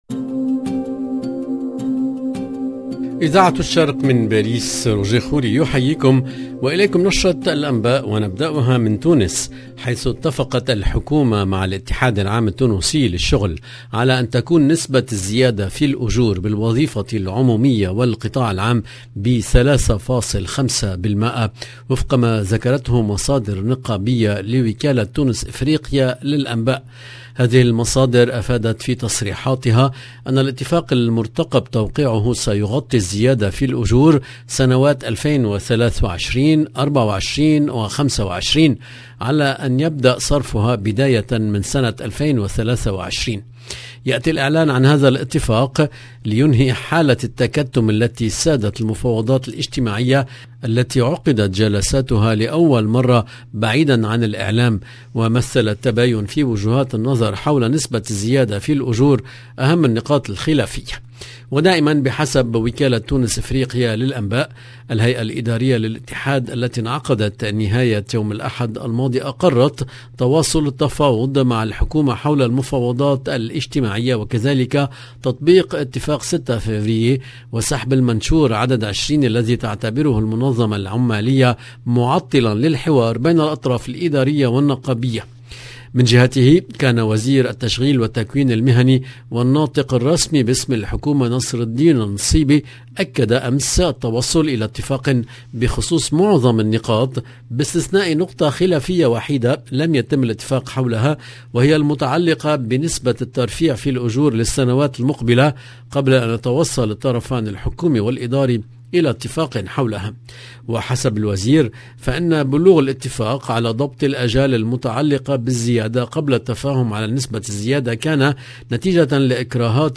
EDITION DU JOURNAL DU SOIR EN LANGUE ARABE DU 14/9/2022